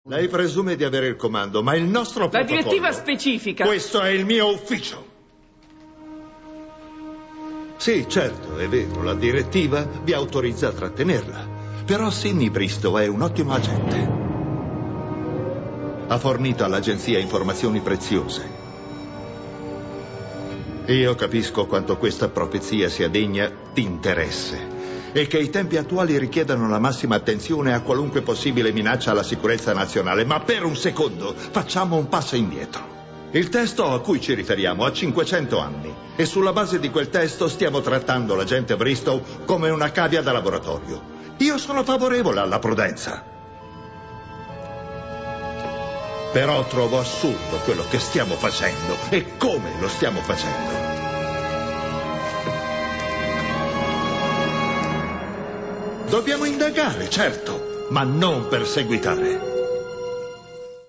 nel telefilm "Alias", in cui doppia James Handy.